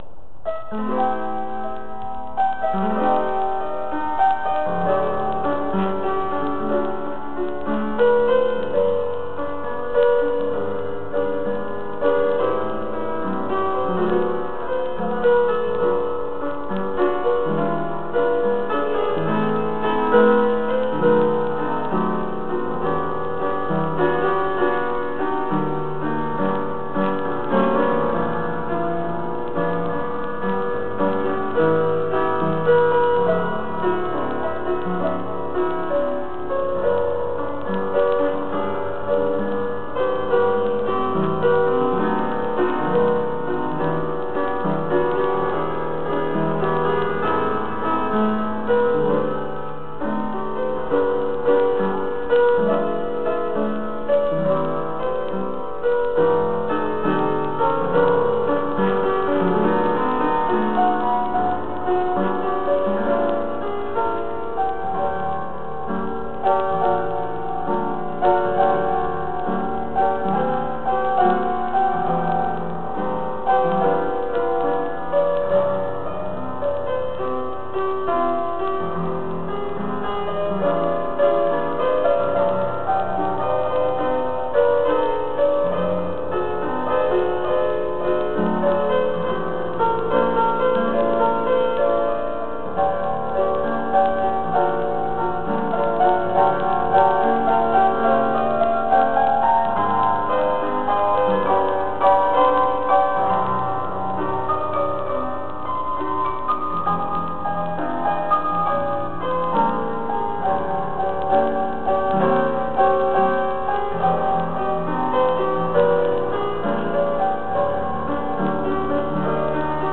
Classical dance lesson, January 6th: warming-up improvisation.